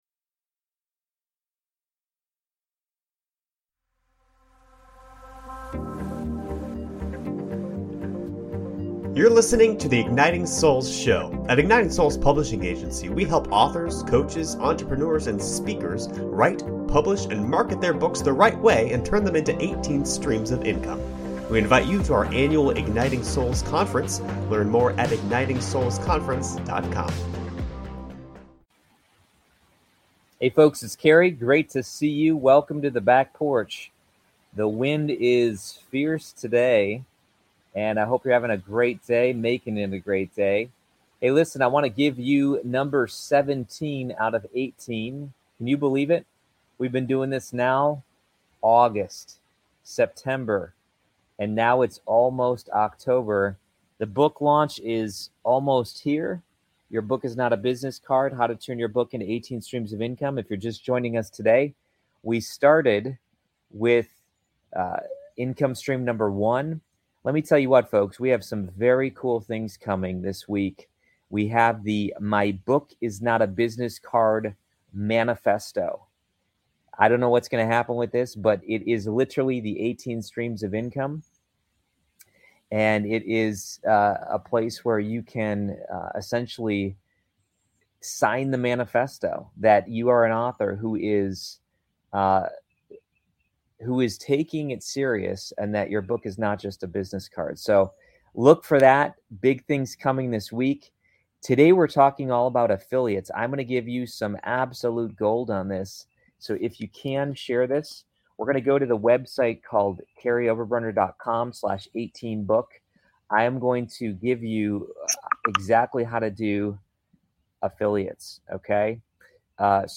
Does God want entrepreneurial-minded missionaries? In this candid chat we explore how God is using The E-Mind™ to spread love and grace around the world.